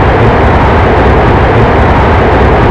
Edit sounds to remove noise and make them loop better
cfm-reverse.wav